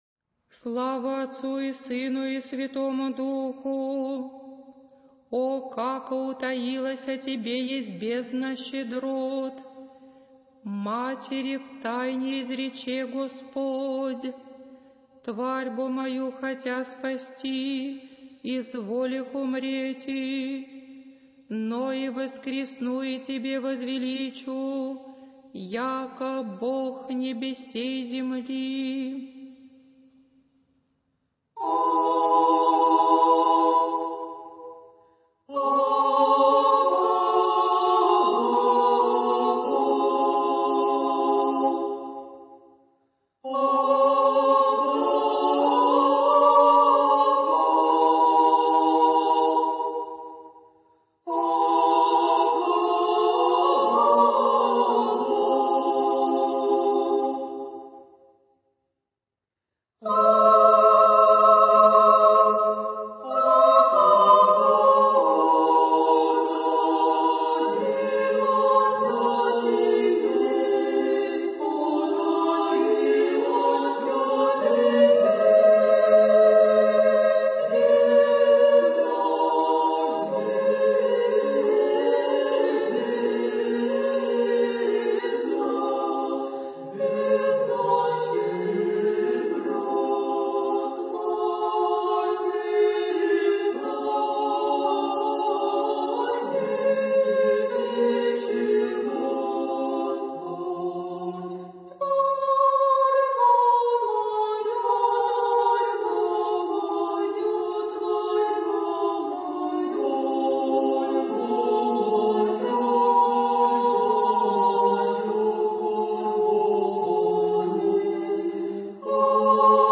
Духовная музыка
Архив mp3 / Духовная музыка / Русская / Хор Свято-Троицкого Ново-Голутвина монастыря / Канон о Распятии и на плач Богородицы /